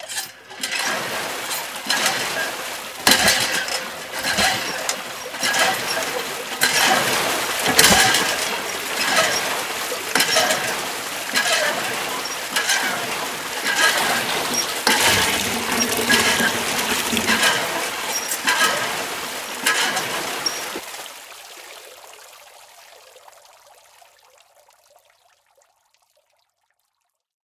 pump.ogg